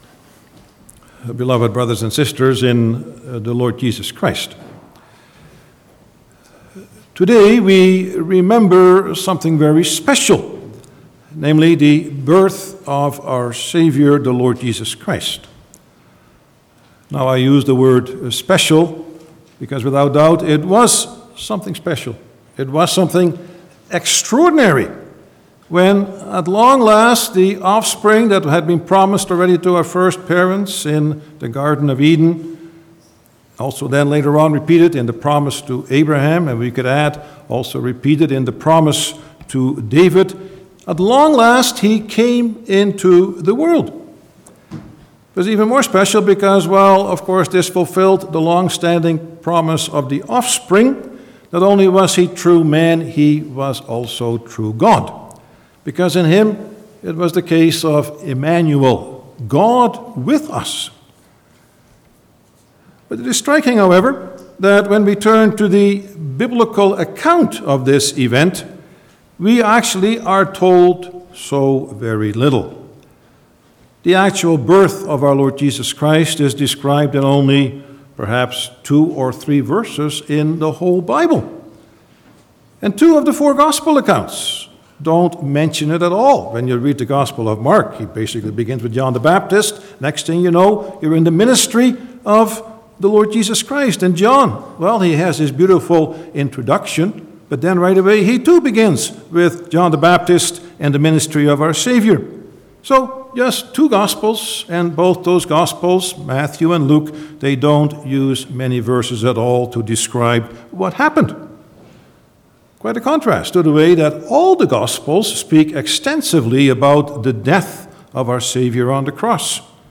Passage: Luke 2:1-7 Service Type: Sunday afternoon
09-Sermon.mp3